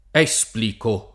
div0to kU#nto p0SSo a tt% SSuppl&ko] (Dante) — caso non isolato, questo di supplico fatto piano, ma paragonabile agli altri di esplicare, implicare, replicare, tutti rifacimenti dòtti di verbi latini della famiglia di plicare «piegare» (con pli- breve), che accanto all’ordinaria pn. sdrucciola delle forme rizotoniche (esplico [